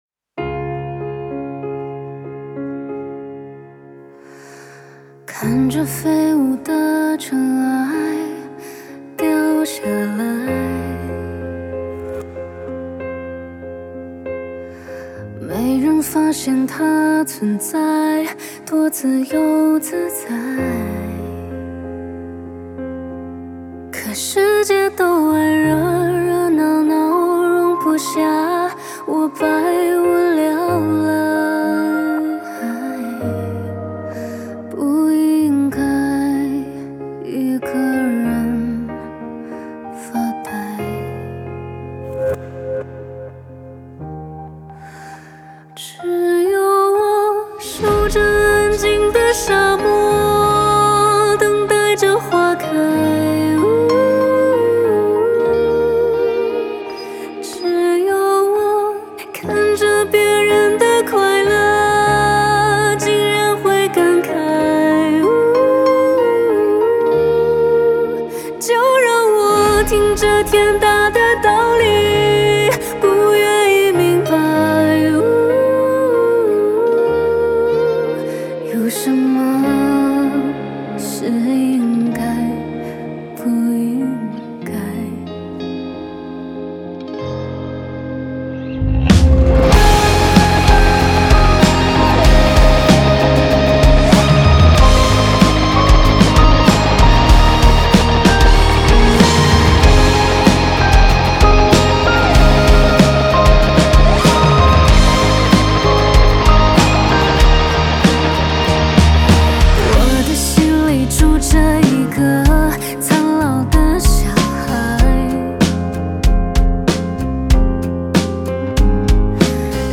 钢琴Piano
贝斯Bass
鼓Drum
吉他Guitar
和声Harmony